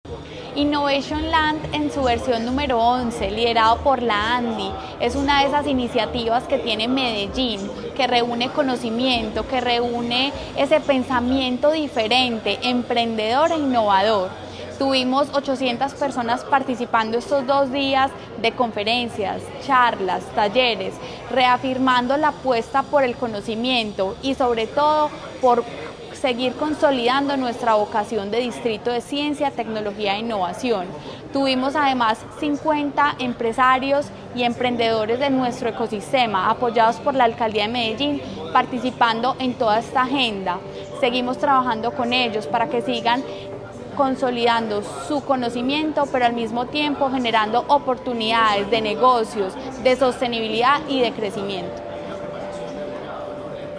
Audio Declaraciones de la secretaria de Desarrollo Económico, María Fernanda Galeano
Audio-Declaraciones-de-la-secretaria-de-Desarrollo-Economico-Maria-Fernanda-Galeano-3.mp3